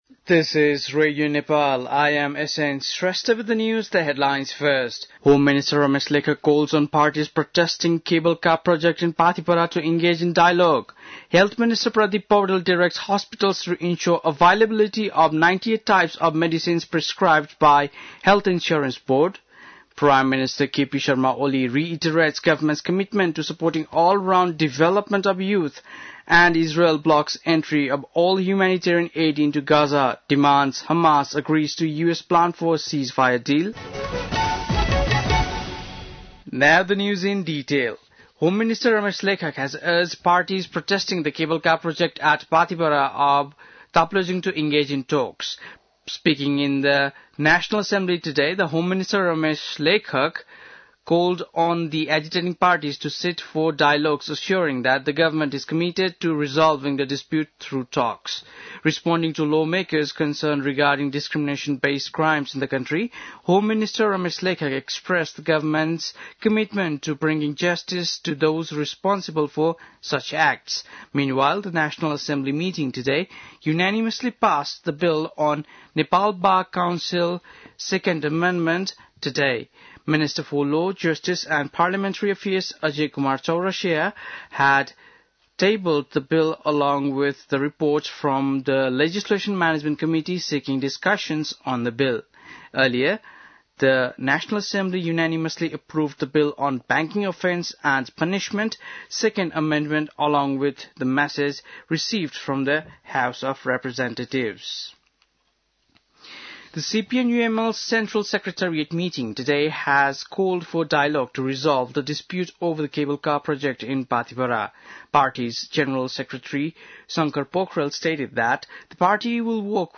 बेलुकी ८ बजेको अङ्ग्रेजी समाचार : १९ फागुन , २०८१
8-pm-english-news.mp3